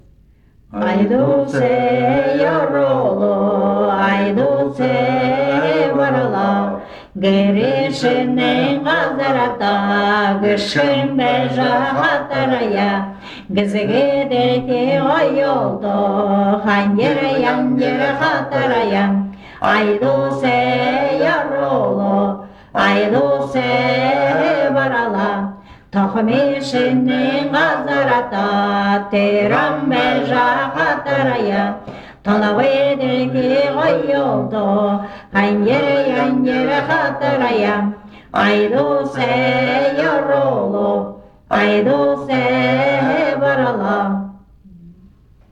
В песнях ёхора можно встретить многоголосие так называемого «ленточного» типа, в котором мелодия излагается параллельными интервалами (терциями, квартами, квинтами), иногда этот параллелизм нарушается.
круговой танец («средняя песня», «обычная песня»)
из с. Баянгол Баргузинского р-на Республики Бурятия